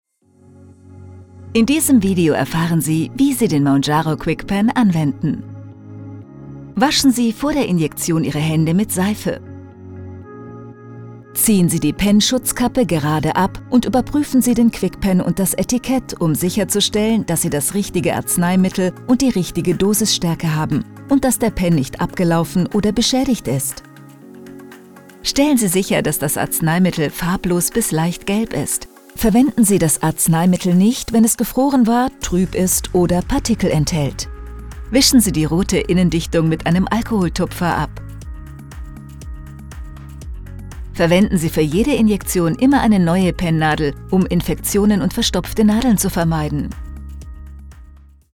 Commercieel, Natuurlijk, Speels, Vriendelijk, Warm
Explainer